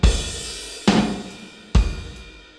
Outro exemplo: sinal de bateria
Usaremos uma gravação de bateria que contém três batidas distintas (baixe drum_seg.wav):
drum_seg.wav